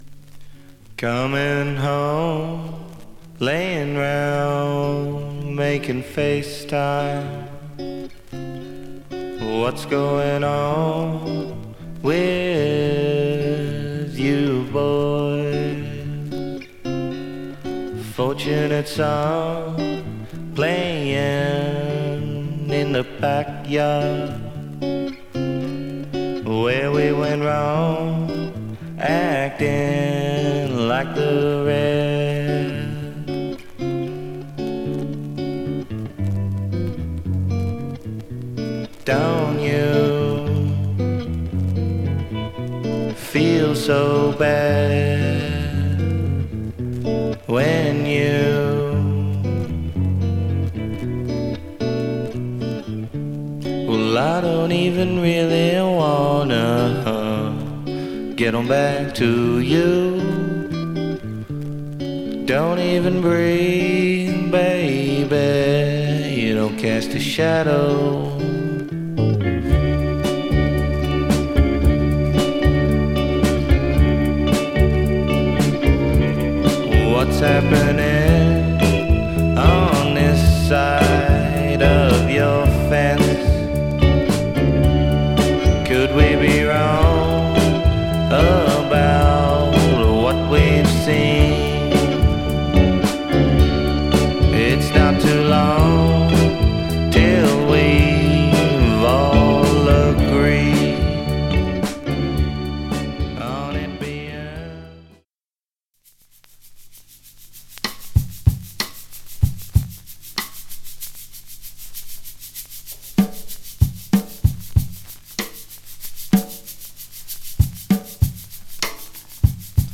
アーストラリアの楽曲は何故こんなに力が抜けているのだろう？
本当良い力の抜け感が心地良いです！